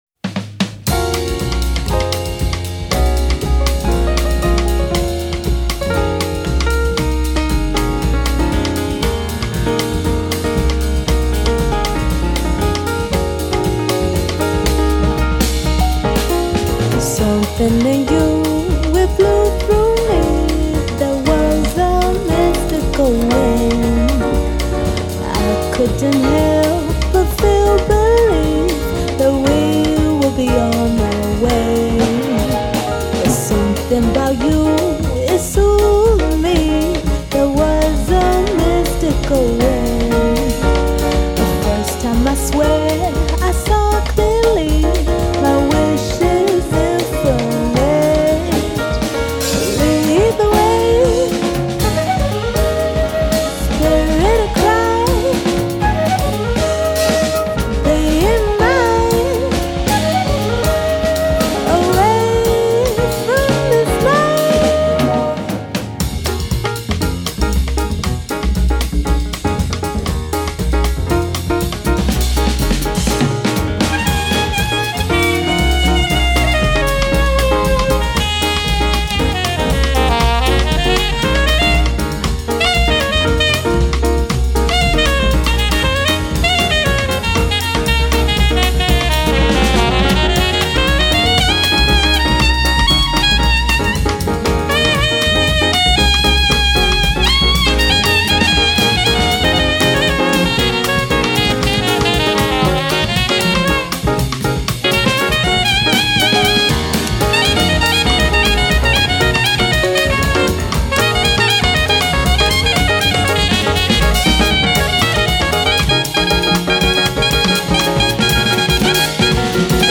Soprano Sax
CP70
Bass
Drums